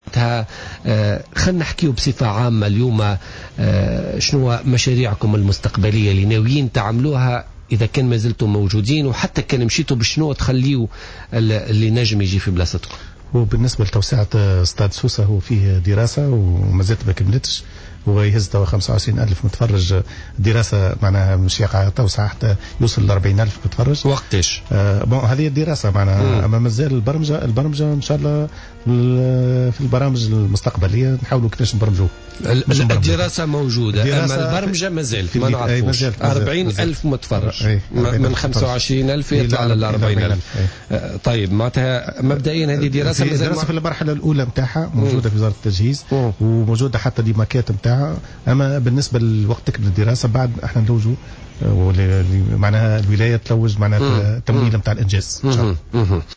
قال والي سوسة عبد الملك السلامي ضيف بوليتيكا اليوم الاربعاء على إذاعة الجوهرة إن مشروع توسعة الملعب الأولمبي بسوسة، لتصبح طاقة استيعابه 40 ألف متفرج، مازالت في طور الدراسة التي لم تكتمل بعد في انتظار مرحلة البرمجة.